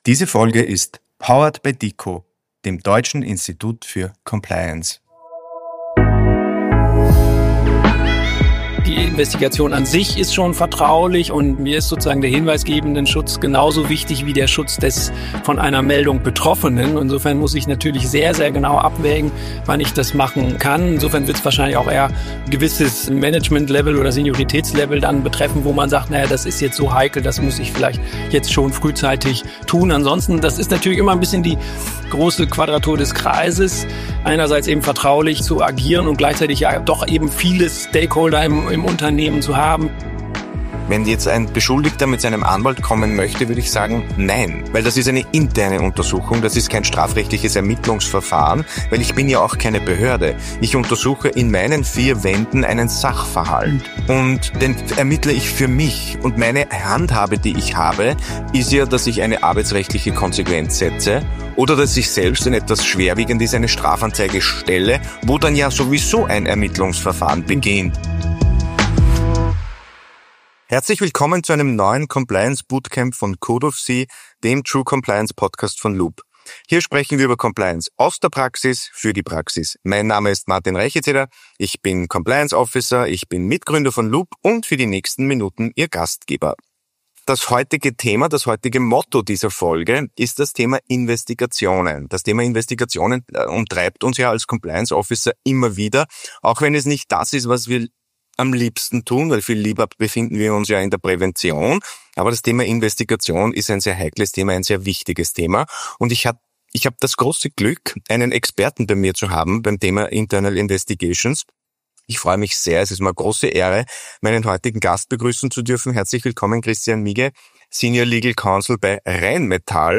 In der letzten DICO-Episode vom DICO-Forum in Berlin